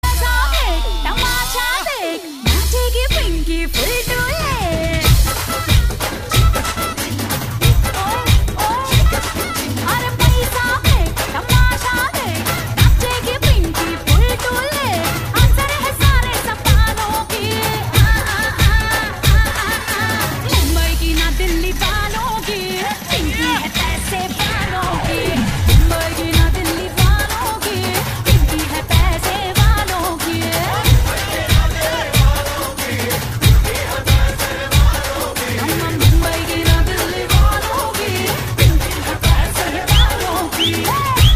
File Type : Navratri dandiya ringtones